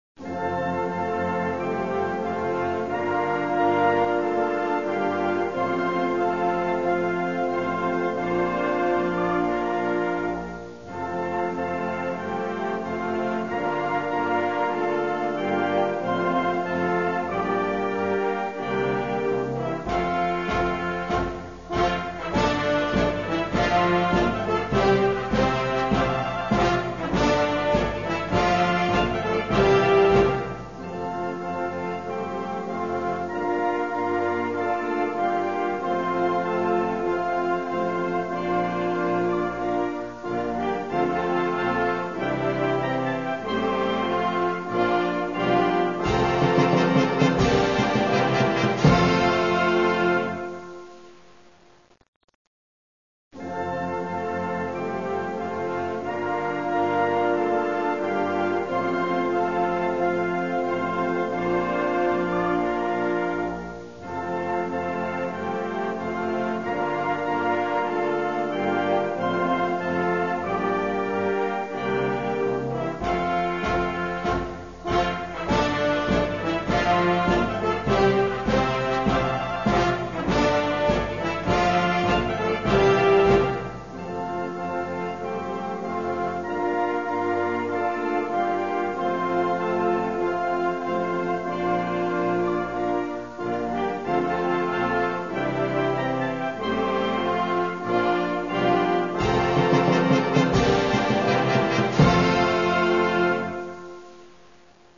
Die Nationalhymne der Deutschen Demokratischen Republik.
Text: Johannes R. Becher
Musik: Hanns Eisler
Instrumentalversion